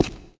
stone02.ogg